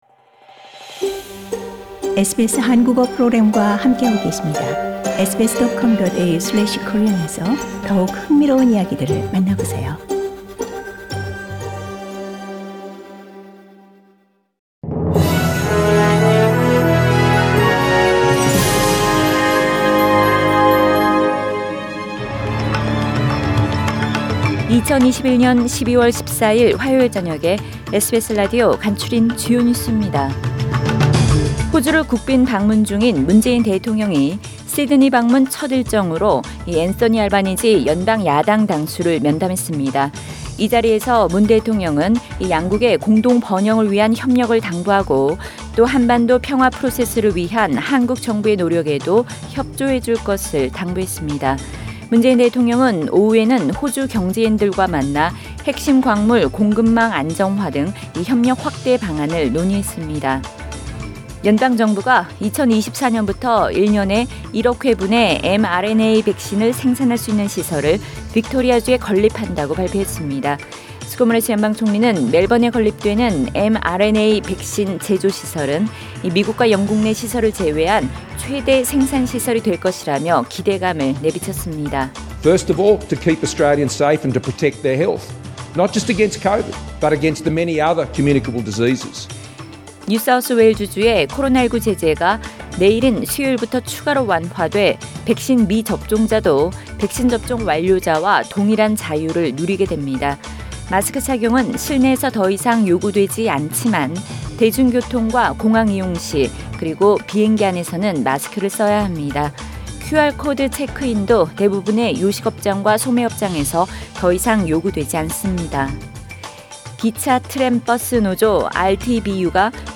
SBS News Outlines…2021년 12월 14일 저녁 주요 뉴스